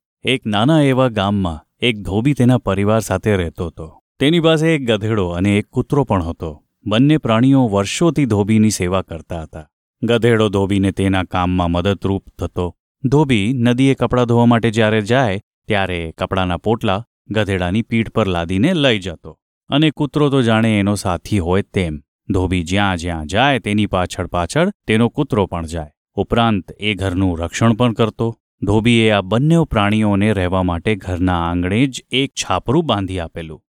Male
Approachable, Bright, Cool, Corporate, Friendly, Natural, Smooth, Soft, Warm
English Indian Accent (Native)
Microphone: Electro Voice RE20